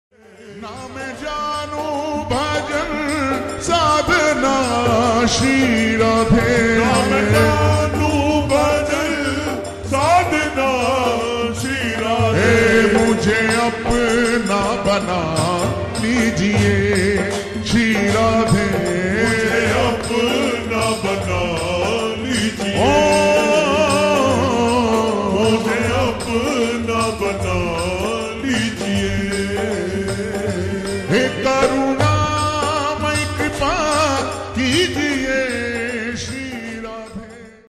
(Slowed + Reverb)
soulful devotional song
meaningful lyrics and serene melody.